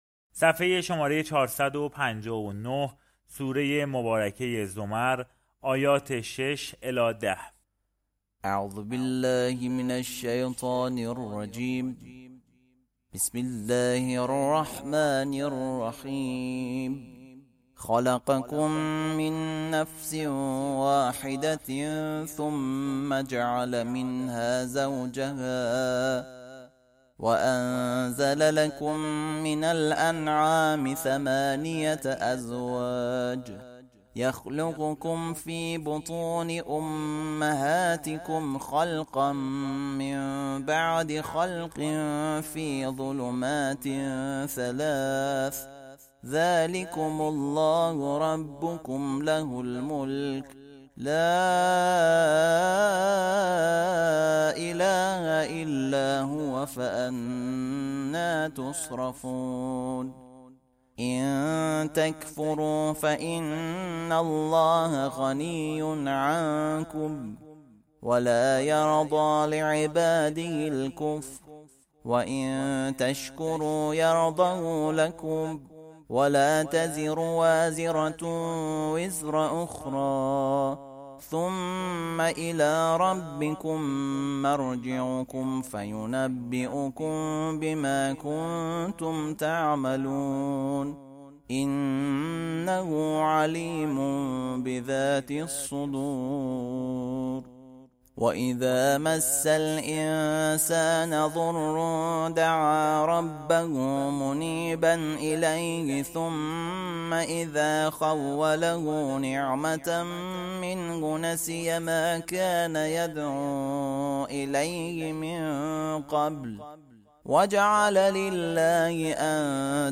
ترتیل صفحه ۴۵۹ از سوره زمر (جزء بیست و سوم)